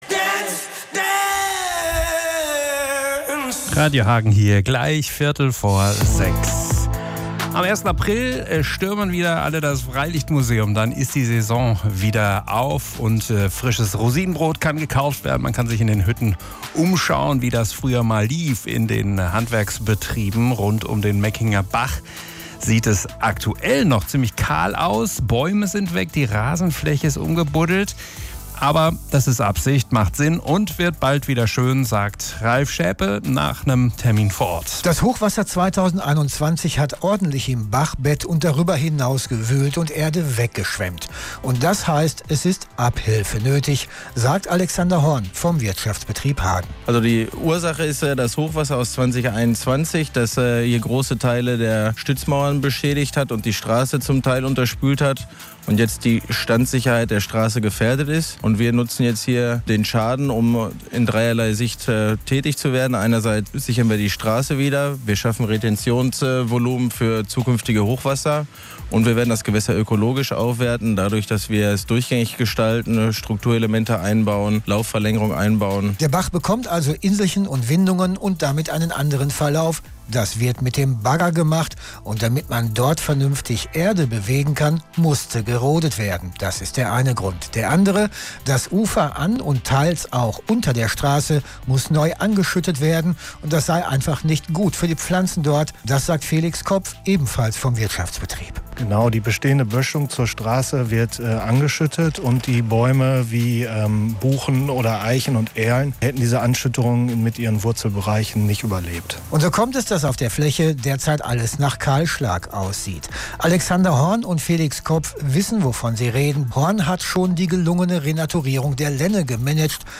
Wir haben im Radio-Hagen-Programm einen ausführlicheren Beitrag dazu gesendet.